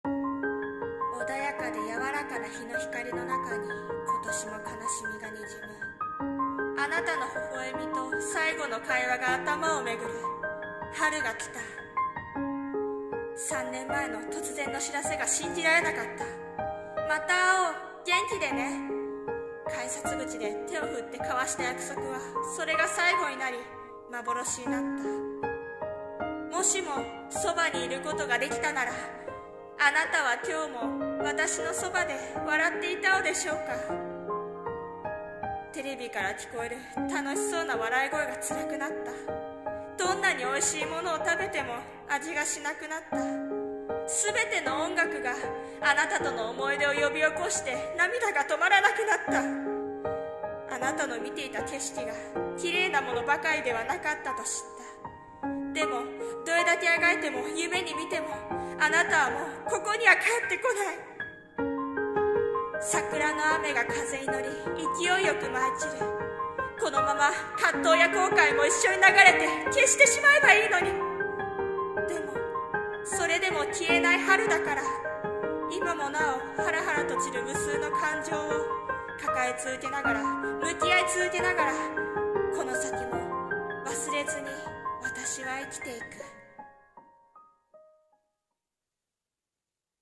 さんの投稿した曲一覧 を表示 【朗読台本】桜の雨